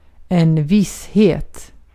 Ääntäminen
Synonyymit assurance Ääntäminen France (Île-de-France): IPA: /sɛʁ.ti.tyd/ Haettu sana löytyi näillä lähdekielillä: ranska Käännös Ääninäyte Substantiivit 1. tillförlitlighet {en} 2. visshet Suku: f .